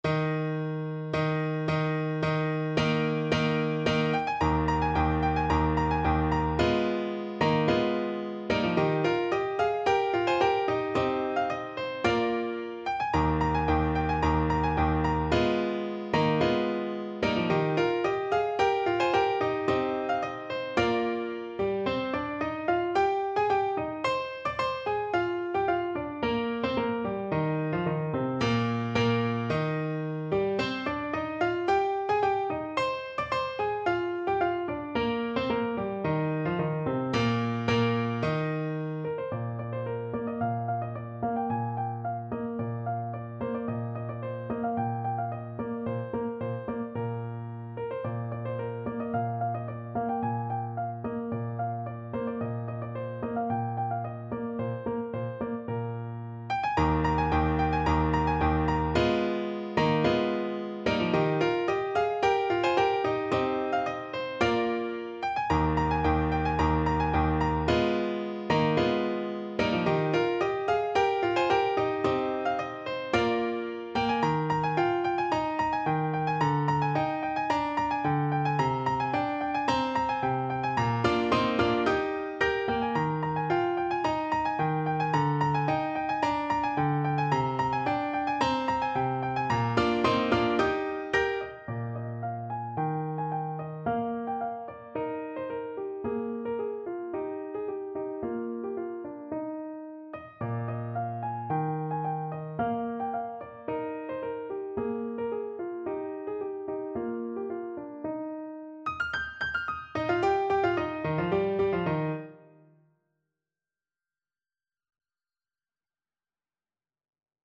Eb major (Sounding Pitch) F major (Trumpet in Bb) (View more Eb major Music for Trumpet )
= 110 Allegro di molto (View more music marked Allegro)
Classical (View more Classical Trumpet Music)